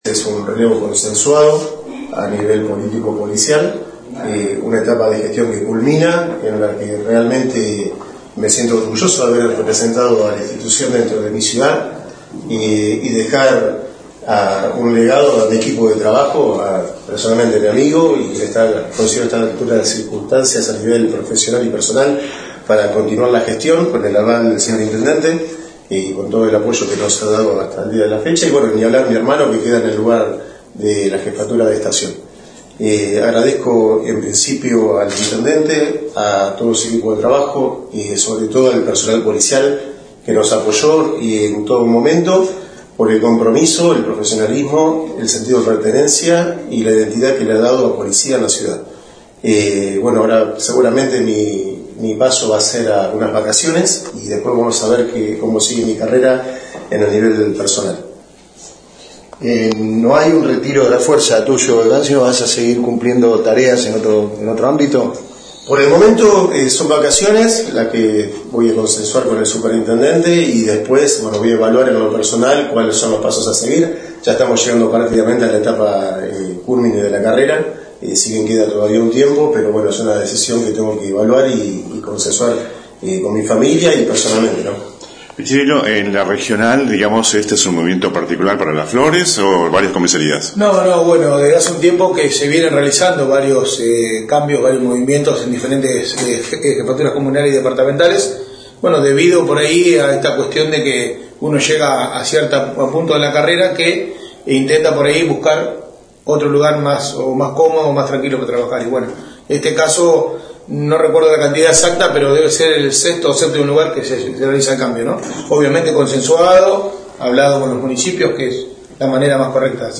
Hoy por la mañana se realizó el cambio de autoridades en la Policía Comunal de Las Flores.
Audio completo Ivan Risso mas conf. de prensa con Comisario Pichirillo y Miguel Paul.